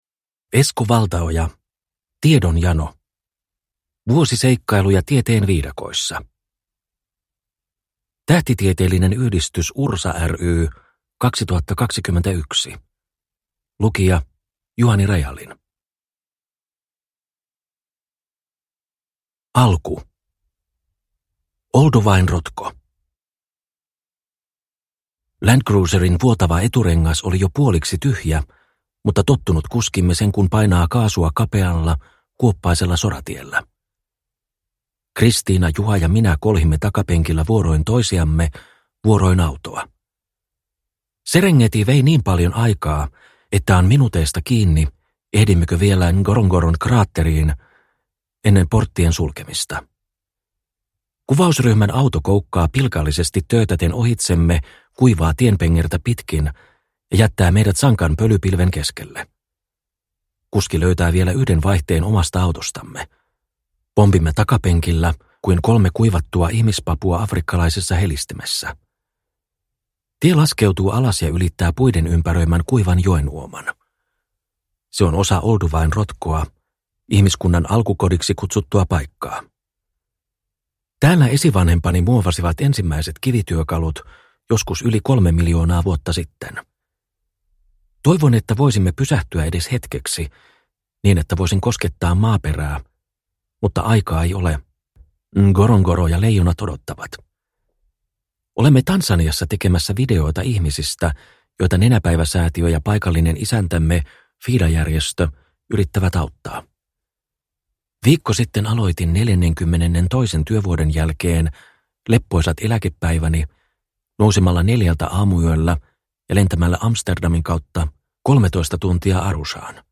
Tiedonjano – Ljudbok – Laddas ner